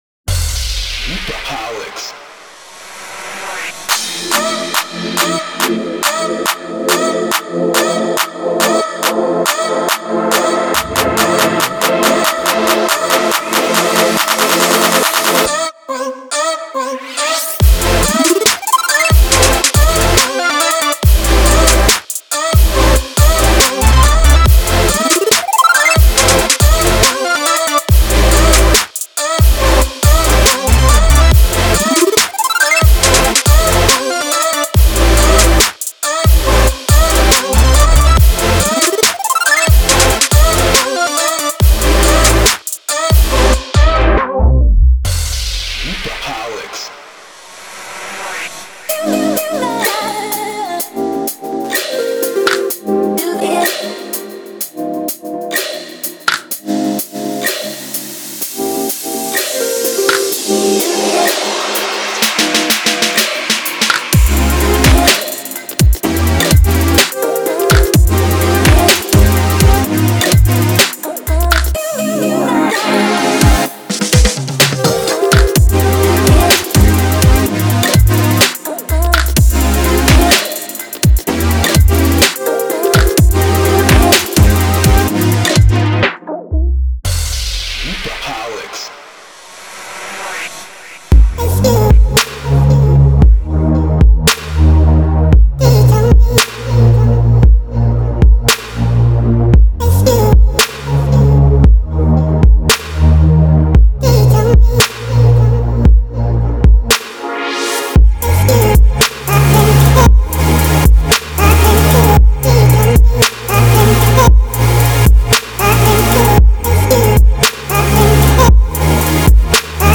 3. Trap
这个包中充满了未来派的合成器，出色的人声切入，充满活力的陷阱鼓，打击垫等等。
所有循环均以57-157 BPM之间的速度记录为24位/44.1kHz质量。